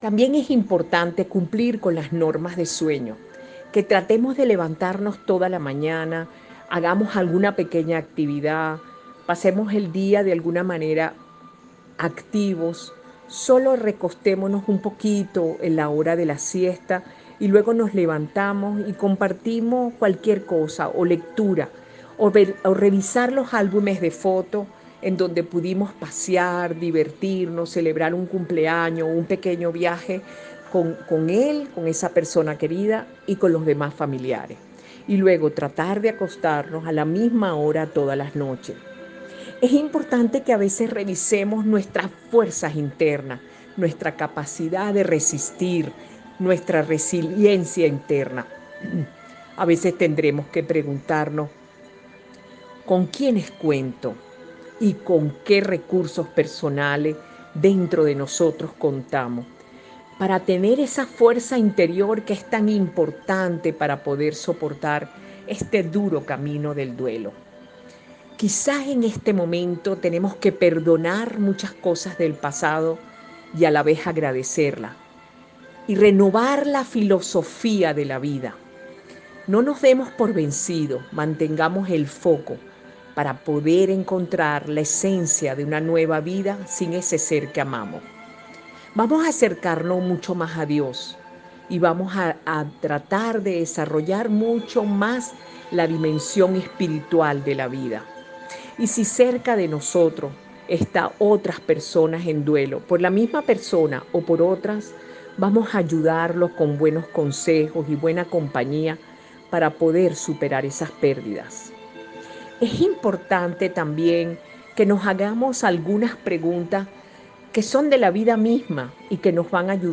A continuación les presentamos los audios con su ponencia.